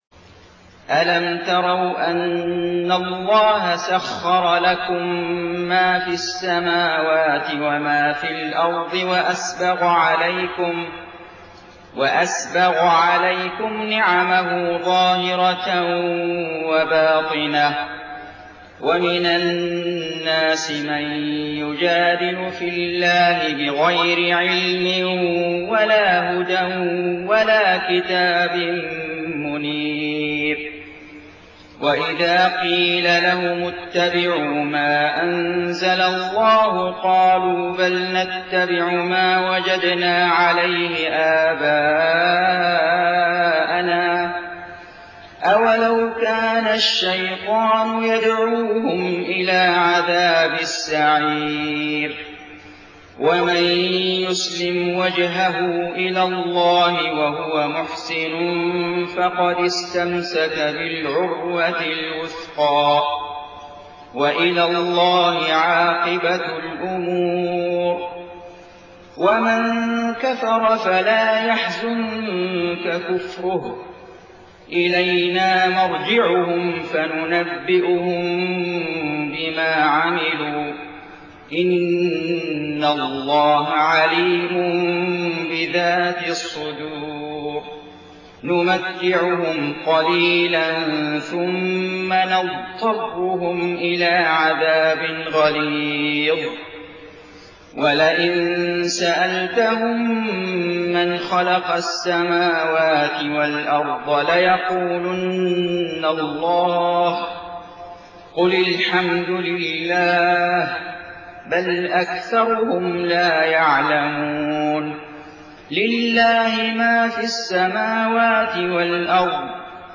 صلاة الفجر 11 رجب 1431هـ من سورة لقمان 20-34 > 1431 🕋 > الفروض - تلاوات الحرمين